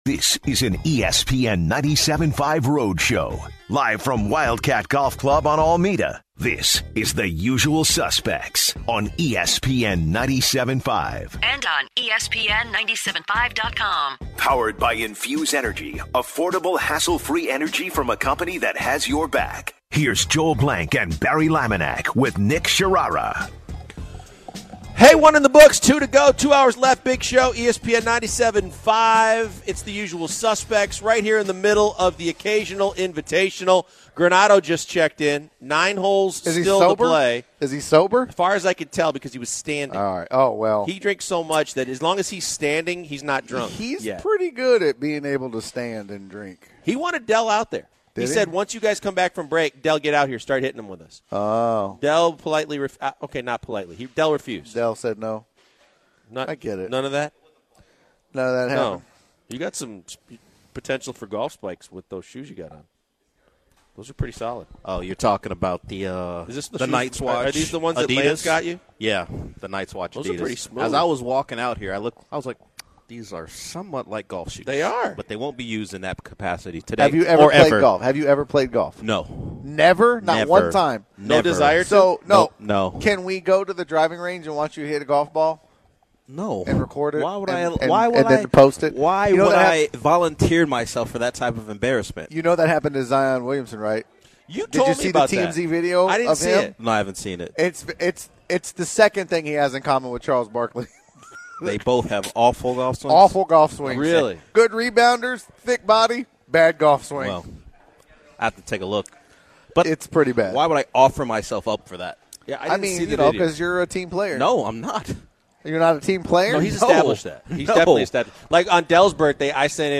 The second hour begins with the guys discussing the parent brawl that occurred at a 7-year-old baseball game and the sucker punch central. Jimmy Butler scares the city of Houston and sends them into a frenzy by posting by the billboards but he has a house in Houston that’s why he is in Houston. The guys continually talk the baseball game and incorporate the golf tournament they are at into the show.